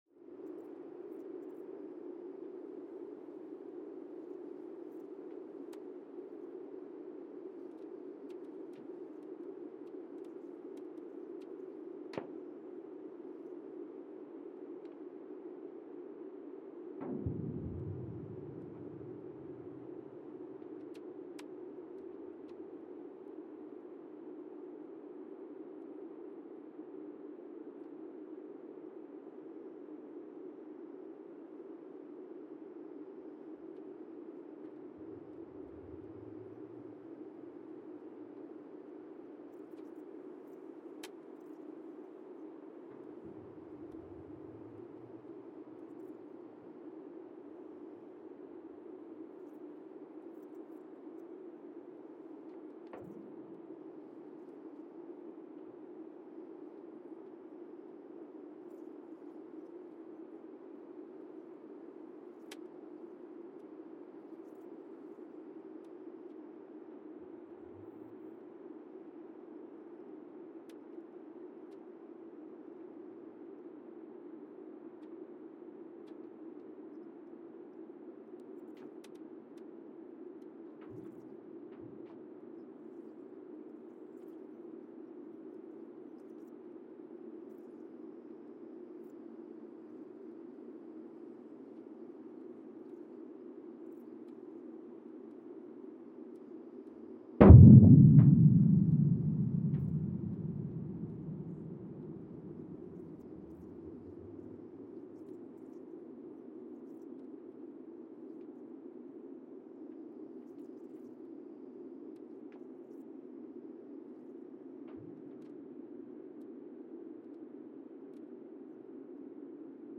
Monasavu, Fiji (seismic) archived on April 28, 2023
No events.
Sensor : Teledyne Geotech KS-54000 borehole 3 component system
Speedup : ×1,800 (transposed up about 11 octaves)
Loop duration (audio) : 05:36 (stereo)